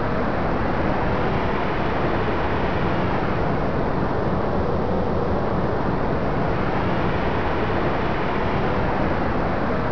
wind2.wav